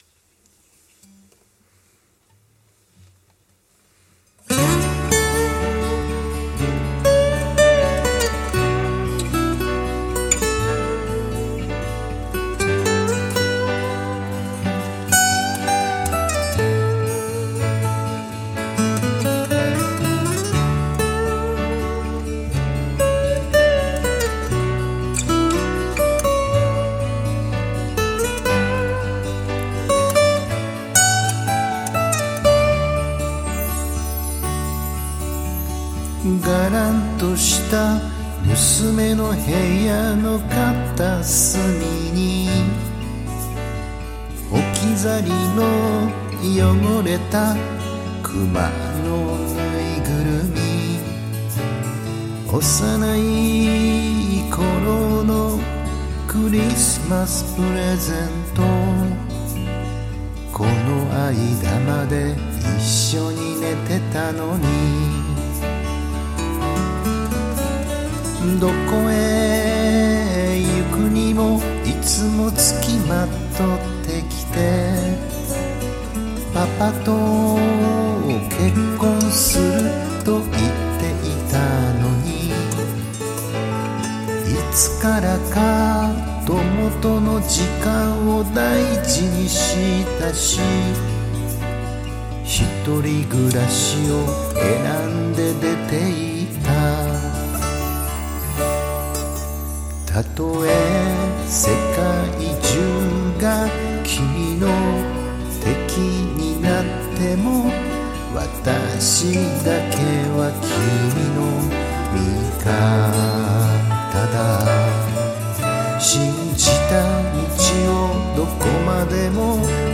カバー曲　　青春時代の曲です